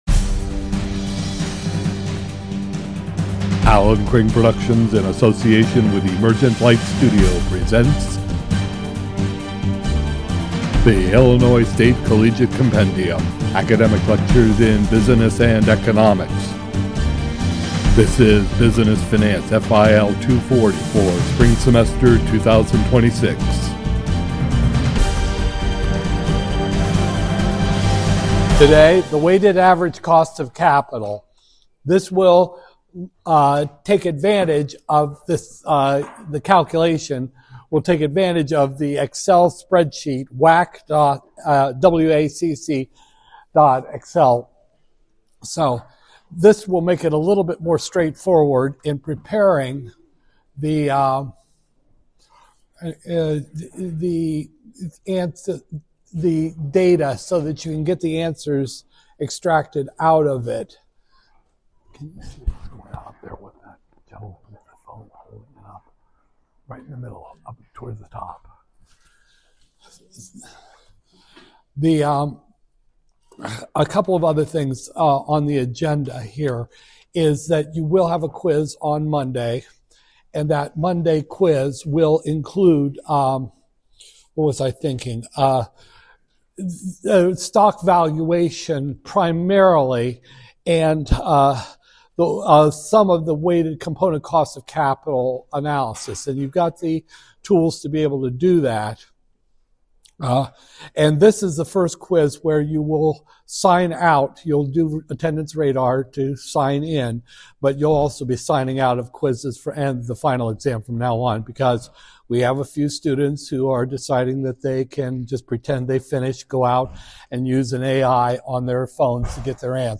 Business Finance, FIL 240-001, Spring 2026, Lecture 20